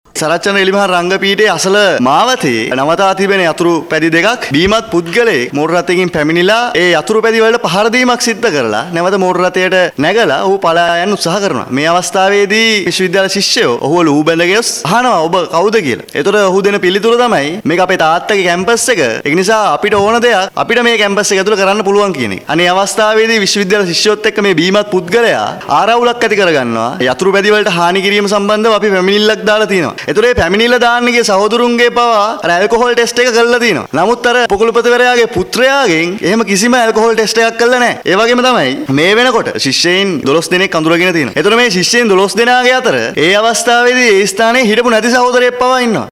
කෙසේ වෙතත් මේ පිලිබදව පැහැදිලි කිරීමට ඊයේ පස්වරුවේ පේරාදෙණි විශ්වවිද්‍යාලයයේ මහා ශිෂ්‍ය සංගමය මාධ්‍ය හමුවක් පැවැත්වුවා .